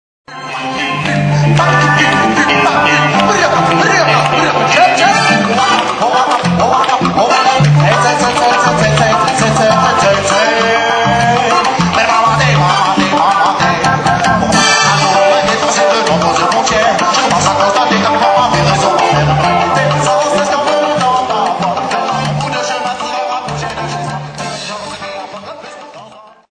Ici, vous pouvez télécharger 4 extraits de la bande son de notre spectacle : si vous avez besoin d'un lecteur cliquez sur RealPlayer